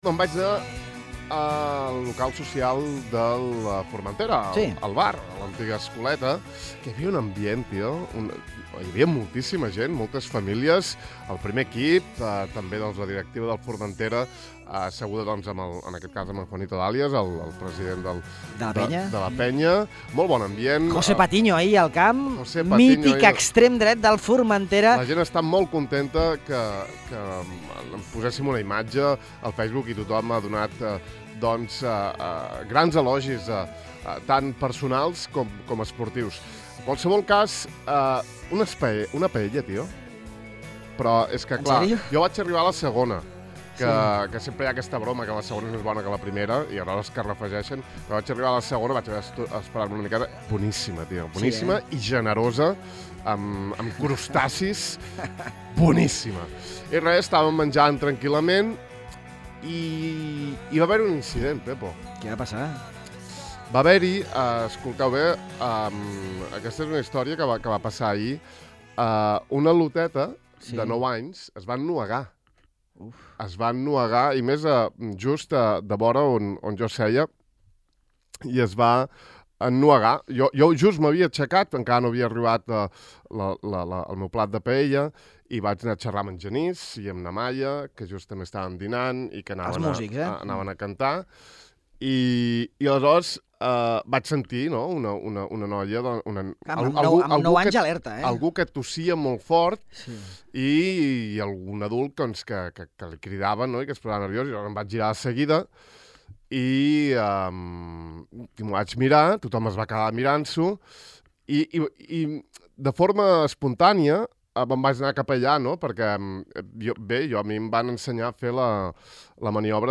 Avui hem volgut saludar-lo i conversar amb ell.